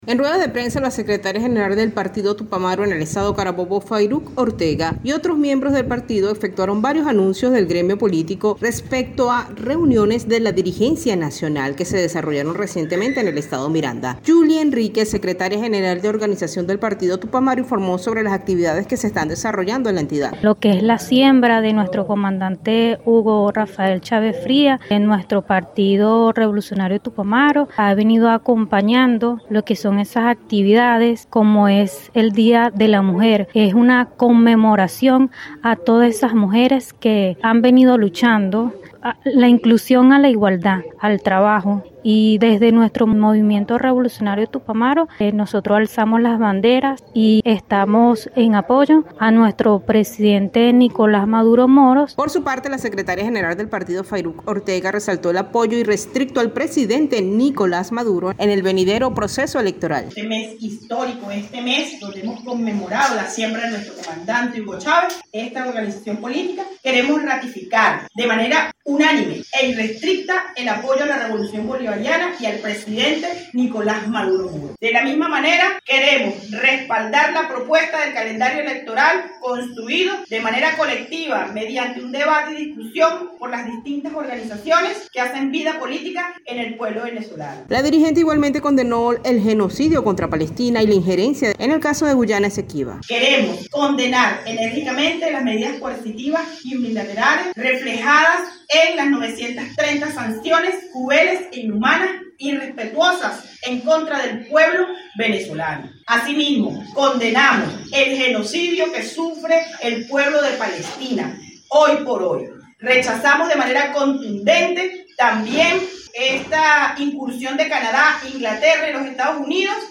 Esta actividad se llevó a cabo en la Aldea Universitaria, ubicada en la urbanización Paraparal en el municipio Los Guayos del estado Carabobo.
Reporte-Tupamaro-apoyo-Maduro.mp3